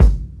ShaMoneyXL_Kick_10.wav